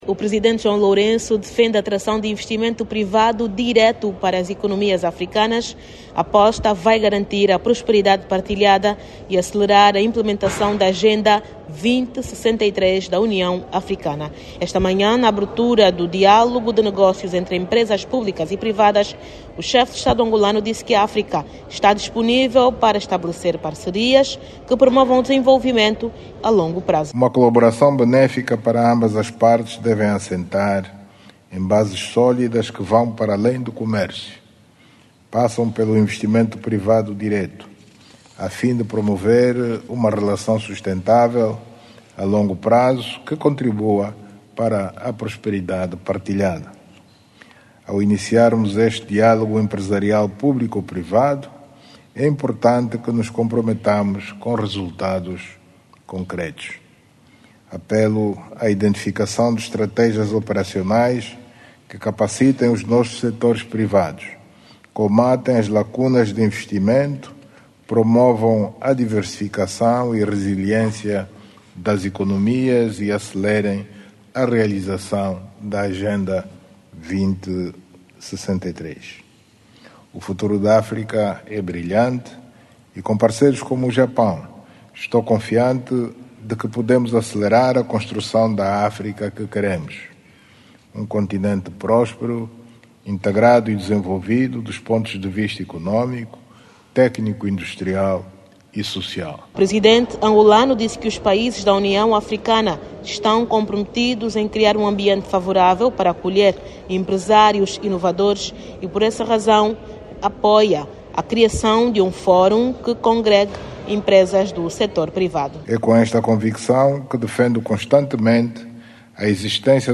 O Chefe de Estado angolano participou esta quinta-feira(21), no diálogo de negócios público privados, disse que África está pronta para parcerias que promovam o desenvolvimento. Clique no áudio abaixo e ouça a reportagem